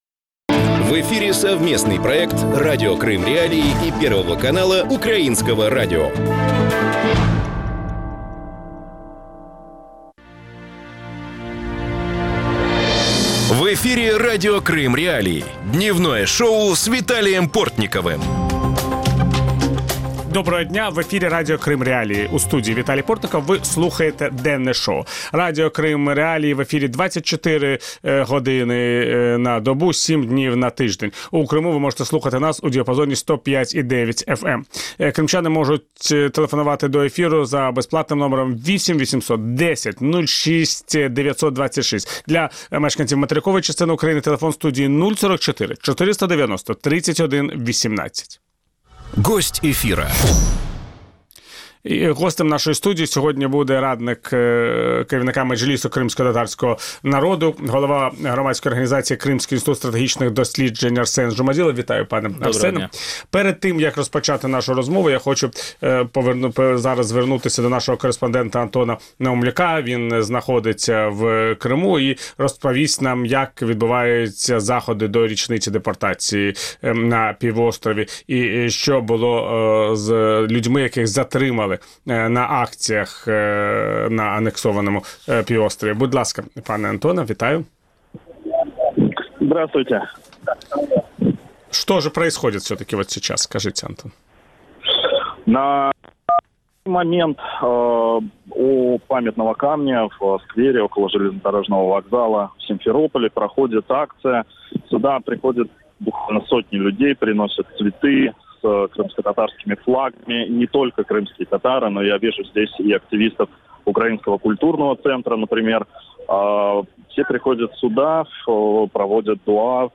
Ведущий – Виталий Портников.